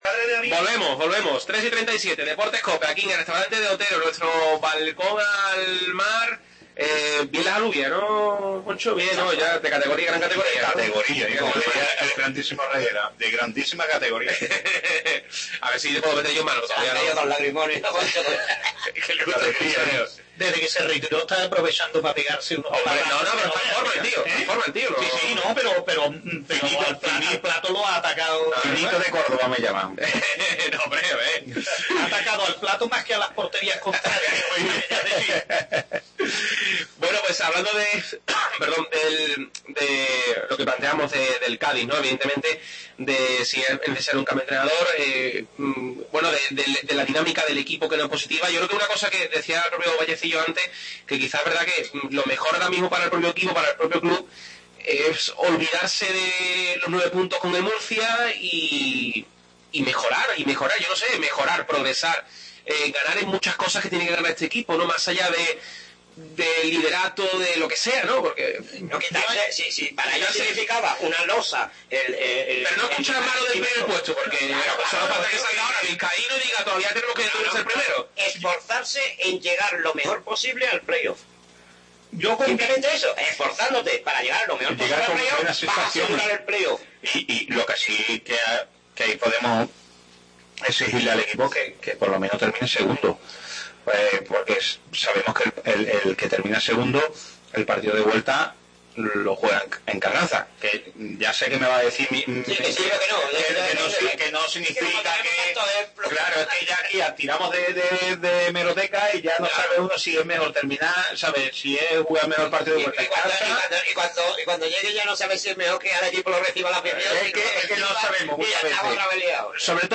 AUDIO: Segunda parte de la tertulia desde el Restaurante De Otero analizando la actualidad del Cádiz CF